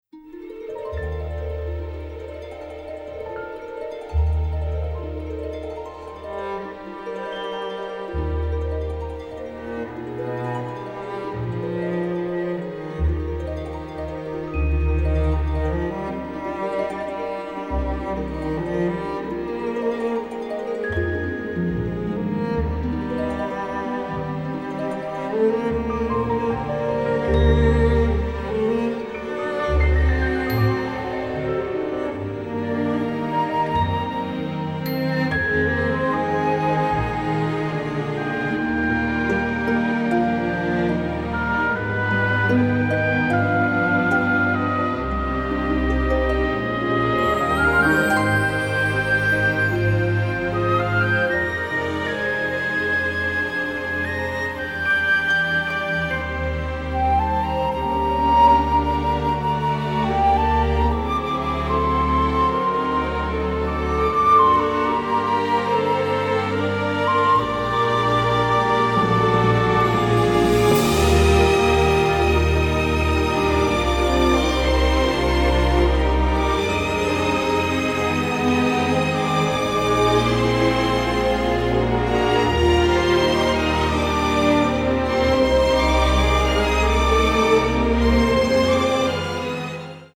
This orchestral album
A nice easy listening version of music